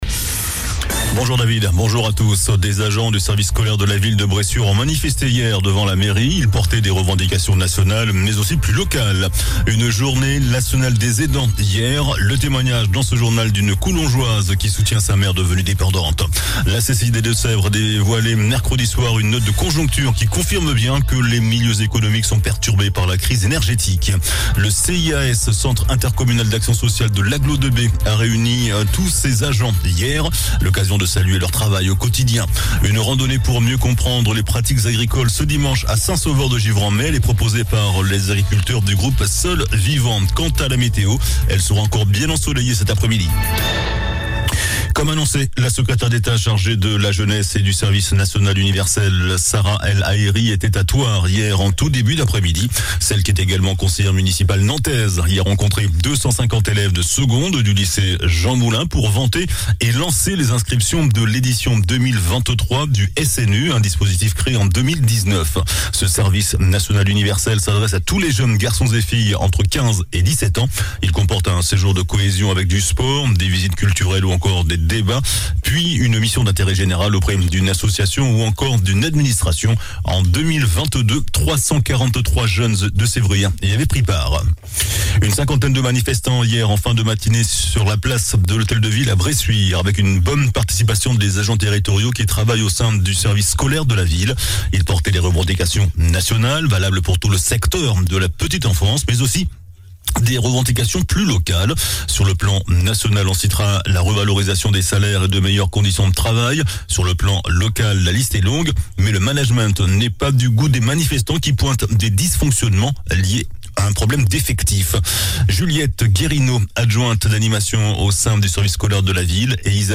JOURNAL DU VENDREDI 07 OCTOBRE ( MIDI )